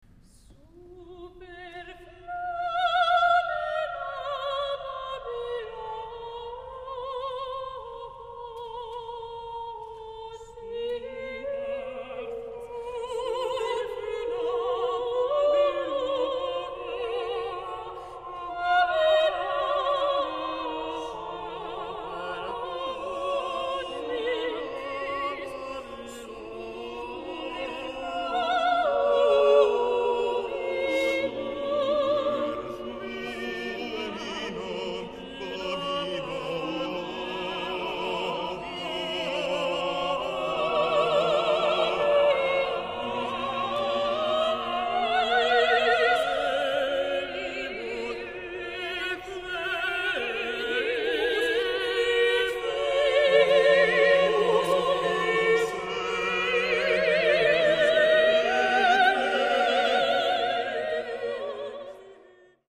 Al centro dell’opera due bolle, con all’interno estranianti microcosmi ironico-dinamici, a formare una sorta di clessidra; in basso e ai lati un lettore cd e cassette acustiche che diffondono due brani musicali di Tebaldini per coro e organo, tratti dalla composizione “Tria Motetta”; dalla sommità emerge una sfera di cristallo come simbolico astro irradiante…